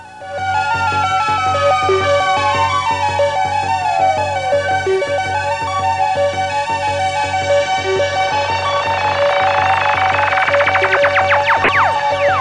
Hi Tech Intro Sound Effect
Download a high-quality hi tech intro sound effect.
hi-tech-intro-1.mp3